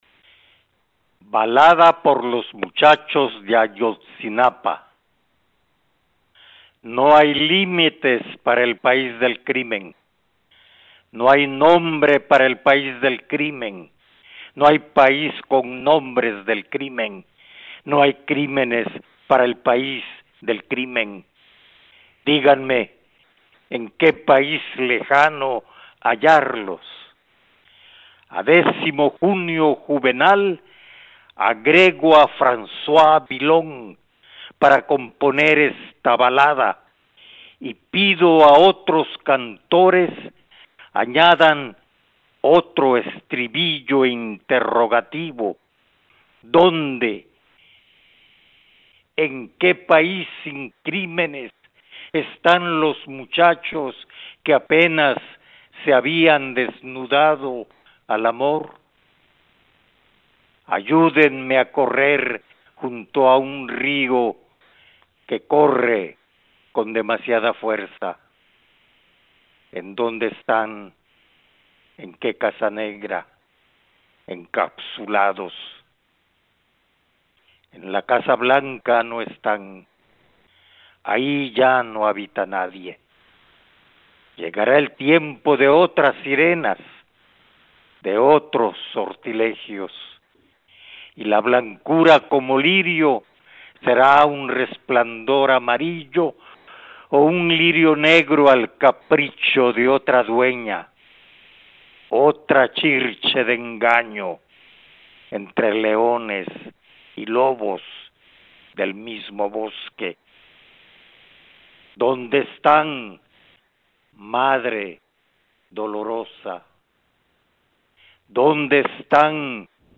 "A lo mejor un poema no puede cambiar al mundo, pero sí puede crear un diálogo y eso es muy importante por todo lo que está pasando", expresó el destacado poeta chiapaneco Óscar Oliva, en el homenaje que recibió por su trayectoria dentro del Segundo Encuentro con la Palabra Escrita, realizado en Tuxtla Gutierrez, Chiapas.
Voz: Óscar Oliva Fecha